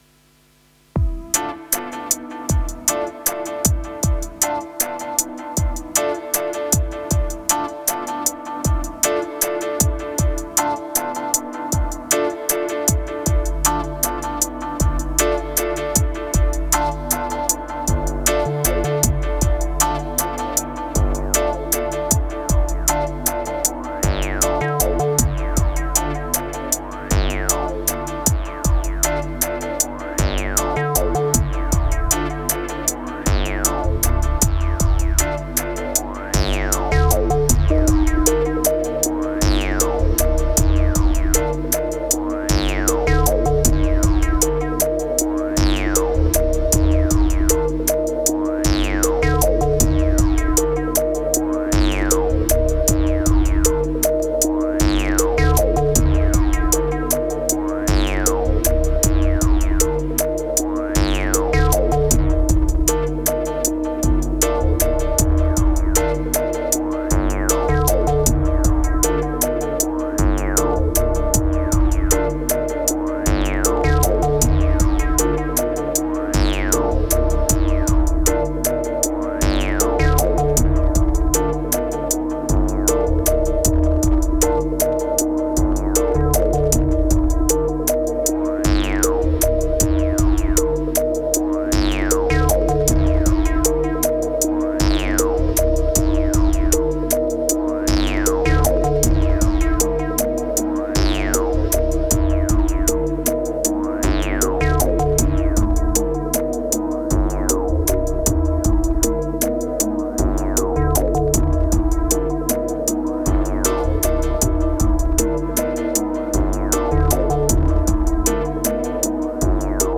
Laidback unity.